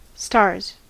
Ääntäminen
Ääntäminen US Tuntematon aksentti: IPA : /ˈstɑɹz/ IPA : /ˈstɑːz/ Haettu sana löytyi näillä lähdekielillä: englanti Stars on sanan star monikko.